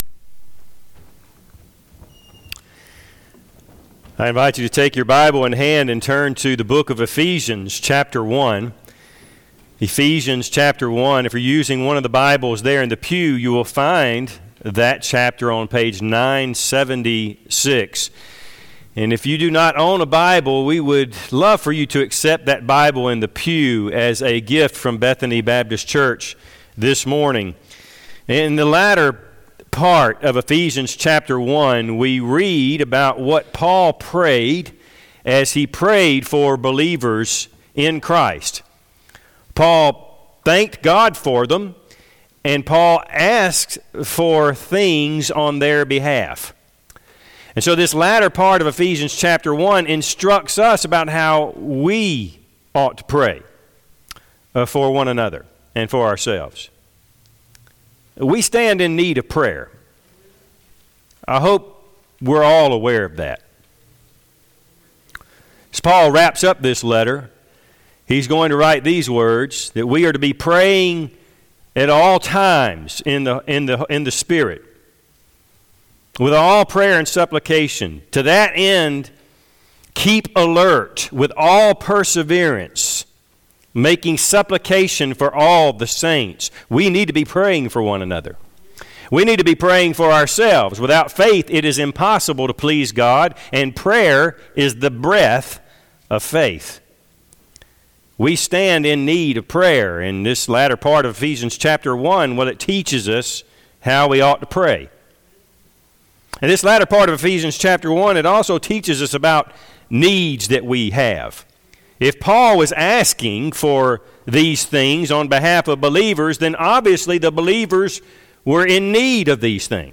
Passage: Ephesians 1:19-23 Service Type: Sunday AM